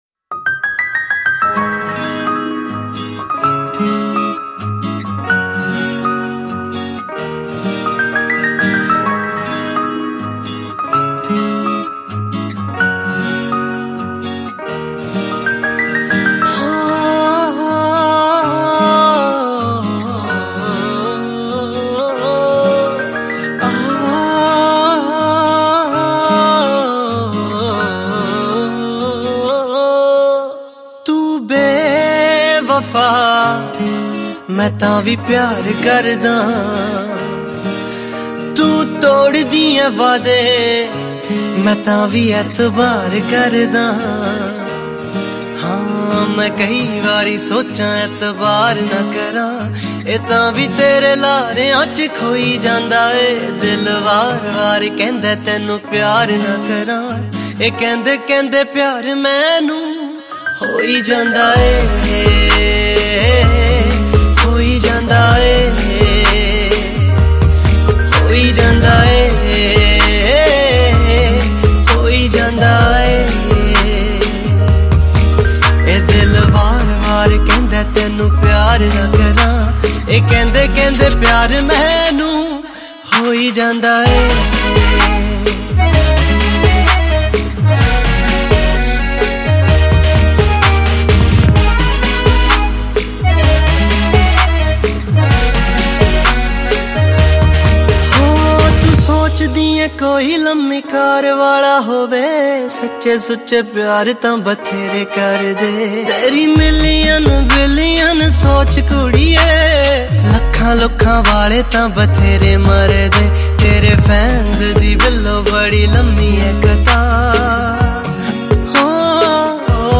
Latest Punjabi song